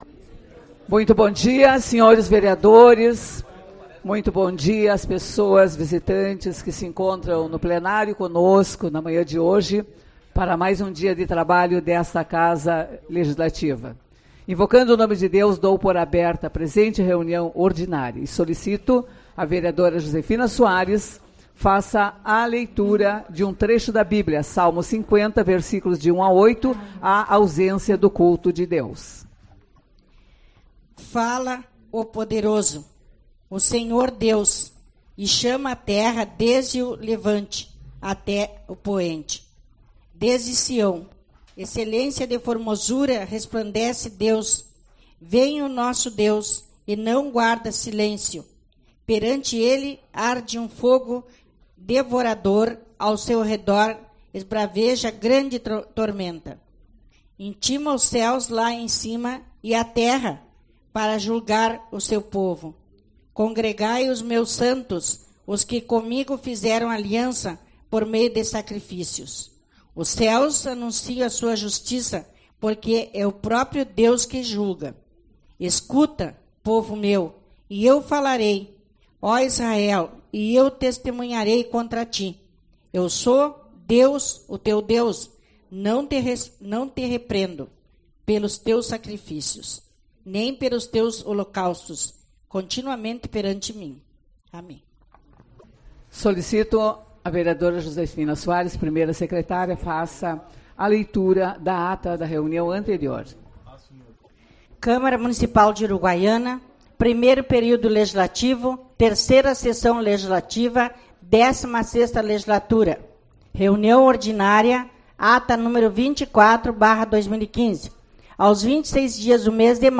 28/05 - Reunião Ordinária